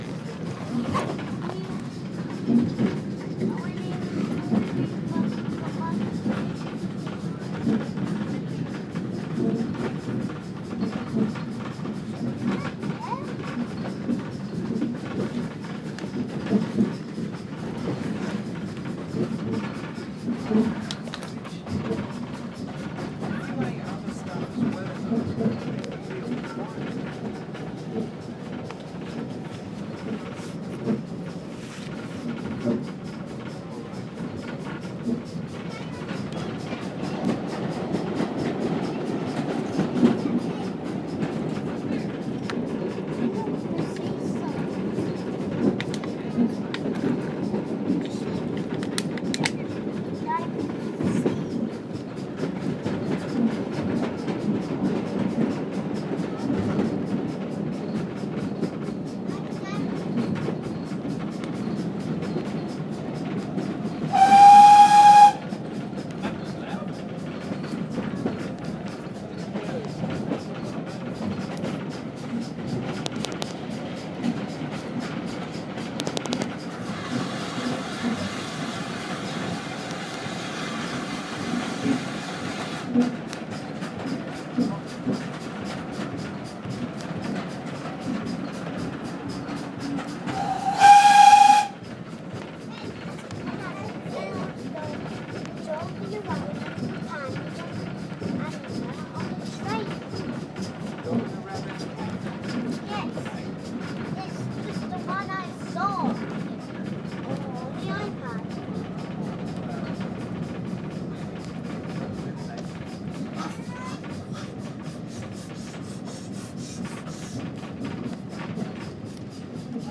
Some journey with whistle and arrival at Goodrington Sands